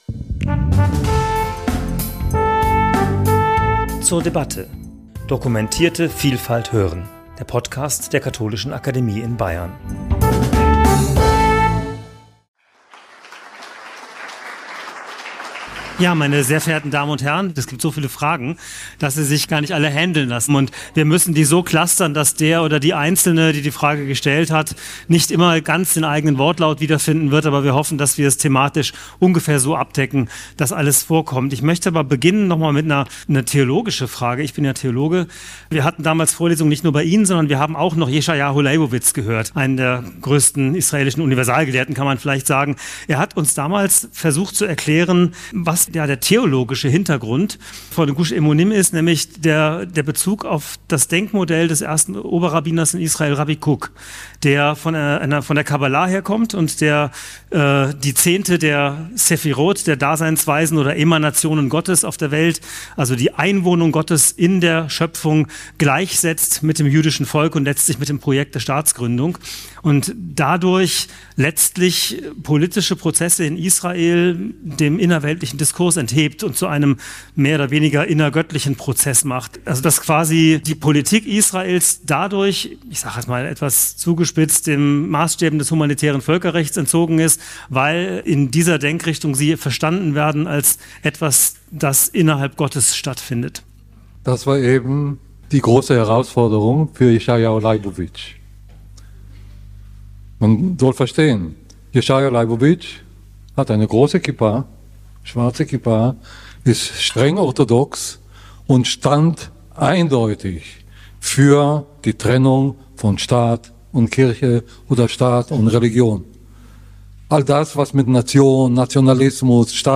Das Gespräch mit Prof. Dr. Moshe Zimmermann zum Thema 'Kann Israel Sicherheit und Frieden finden?' fand am 24.6.2024 in der Katholischen Akademie in Bayern statt.